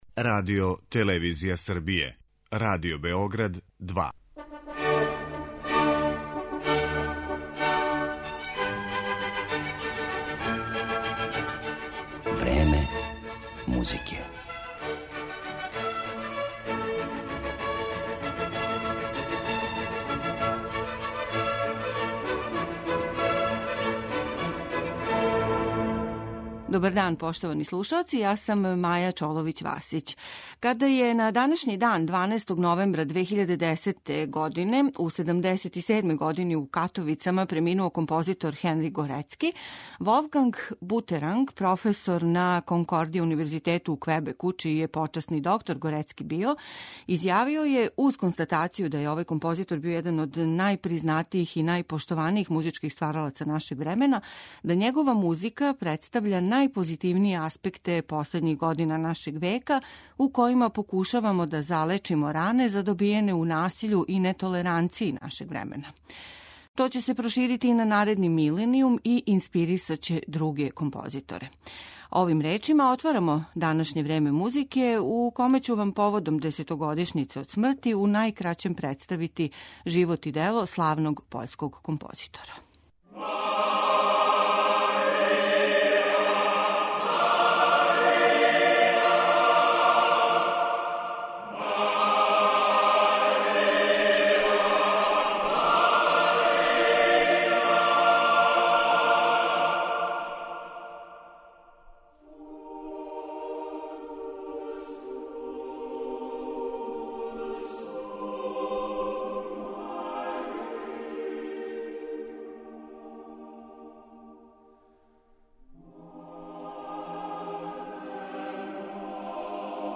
У данашњој емисији ћете слушати фрагменте Трећег гудачког квартета, Концерта за клавир, вокалних дела Totus Tuus и Mizerere, као и један став Симфоније тужних песама ‒ његовог најпопуларнијег дела, које му је донело светску славу и (за већину уметника класичне музике незамислив) тираж од преко милион продатих плоча.